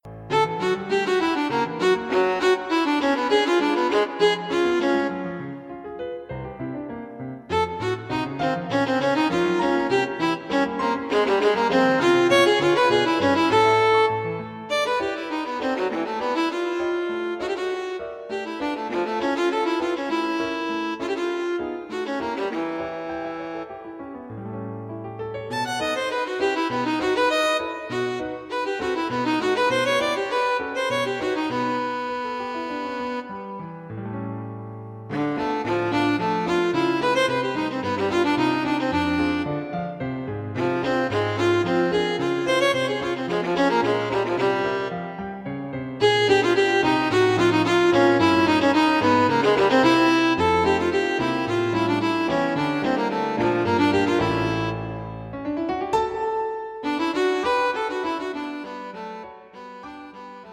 Hear an excerpt from .Viola Sonata (3rd Movement) (Mp3)